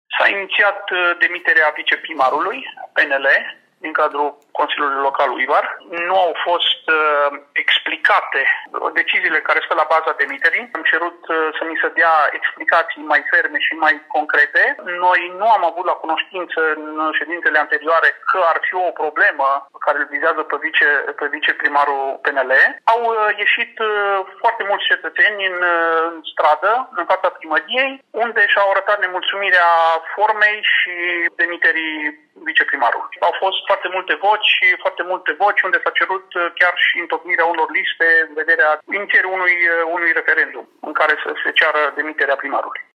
Liberalii spun că nu cunosc motivele schimbării, însă oamenii cer repunerea în funcție, spune consilierul local Ioan Vasian.